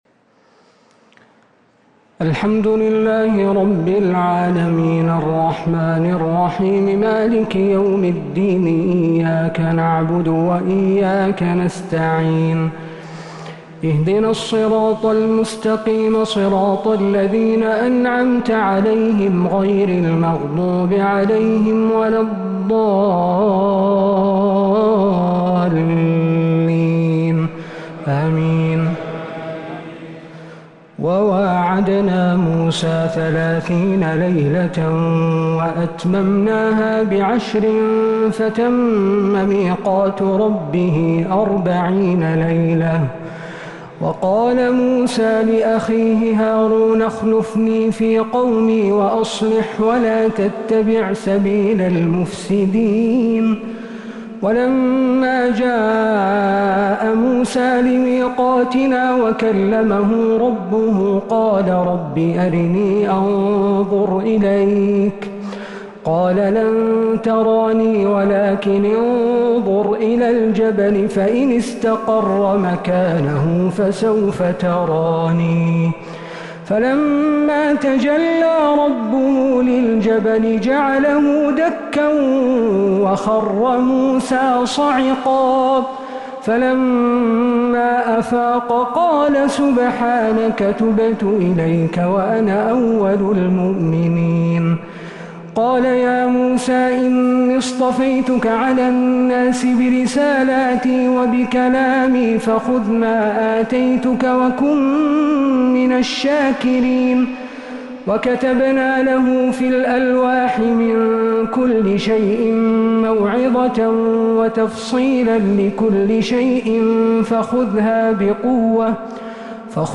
تراويح ليلة 12 رمضان 1447هـ من سورة الأعراف (142-200) | taraweeh 12th niqht Surat Al-Araf 1447H > تراويح الحرم النبوي عام 1447 🕌 > التراويح - تلاوات الحرمين